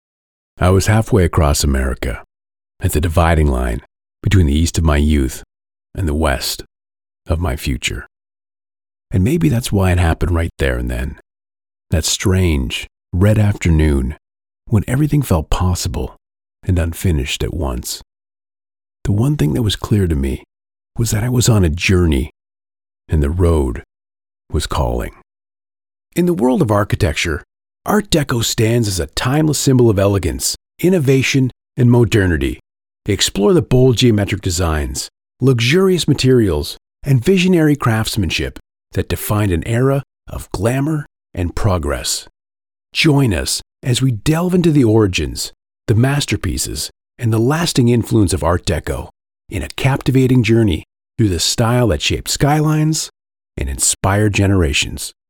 20s-40s. US. Mid-to-deep tone. Steady and firm American voice. Home Studio.
Home Studio Read